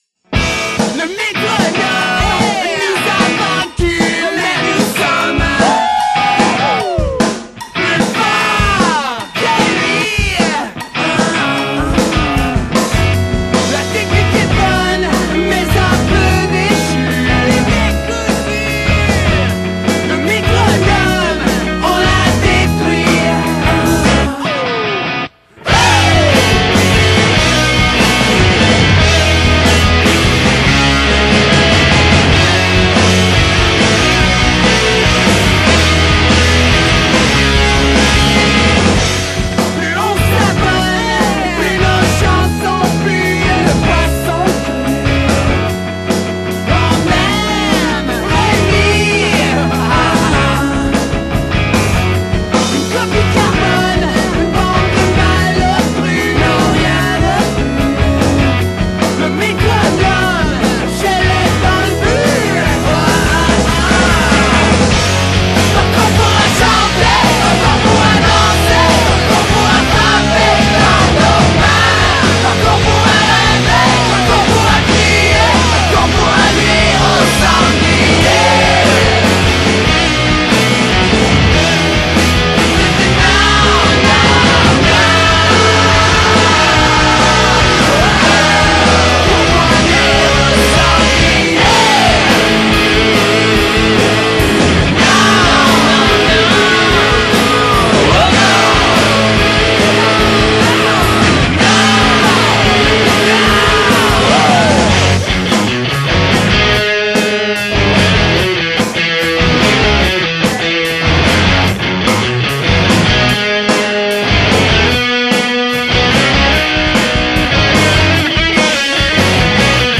• Genre: Rock / Indie
französischsprachige Indie-Rock-Band
die mit frischer und melodischer Musik auftrumpft.